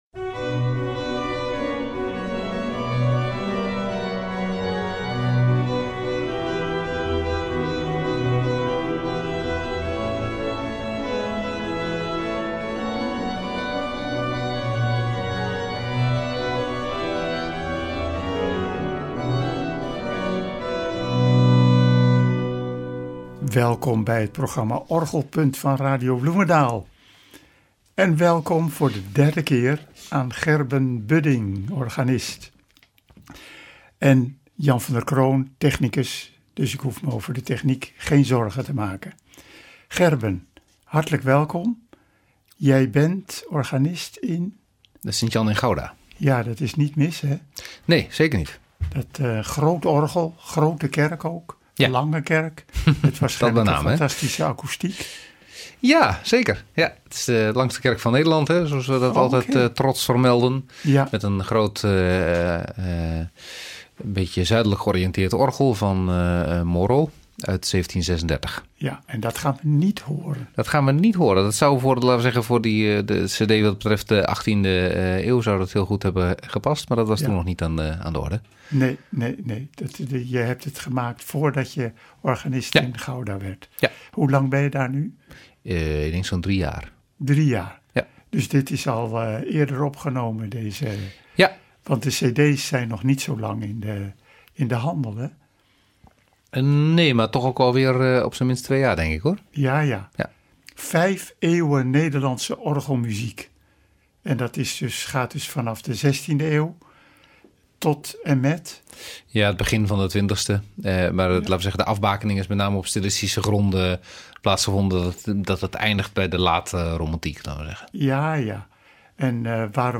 gespeeld op orgels in Gorinchem en Tielt (B), die passen bij die tijdsperiode.